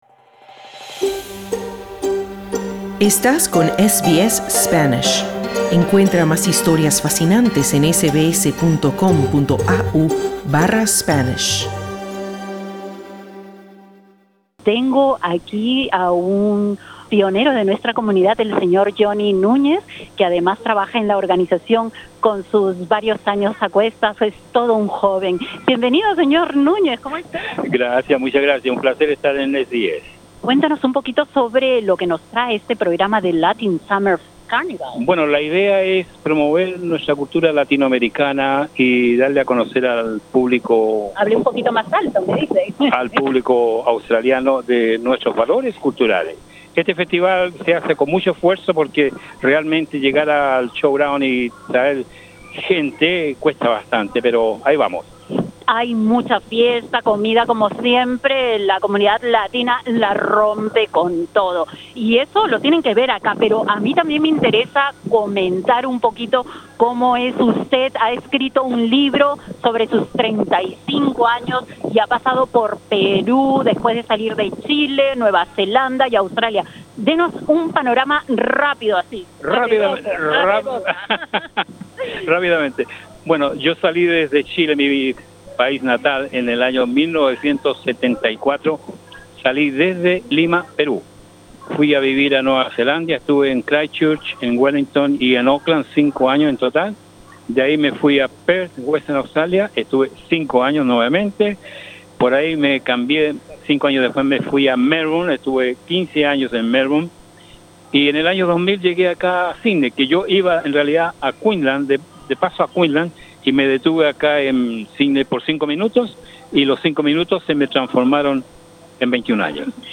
Si quieres escuchar la breve entrevista, presiona la imagen principal.